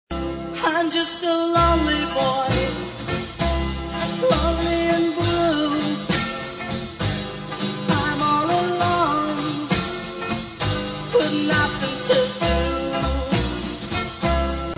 singing the chorus